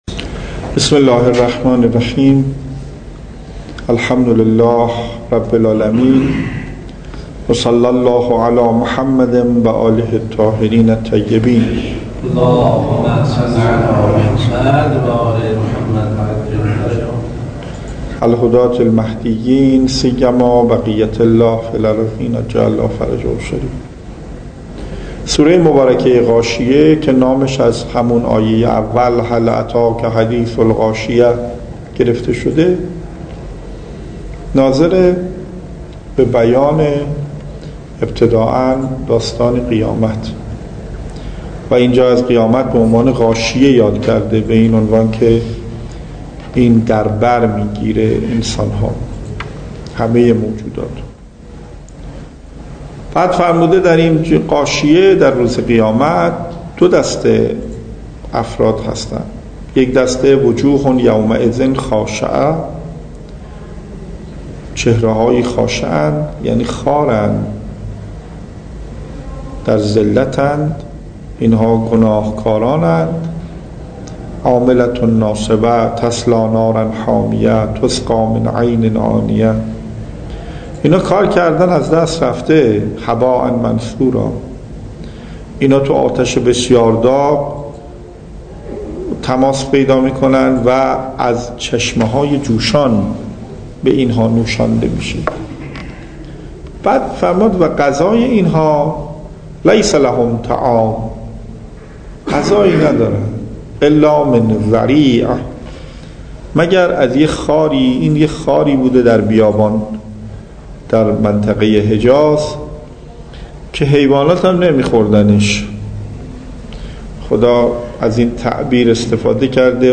تفسیر قرآن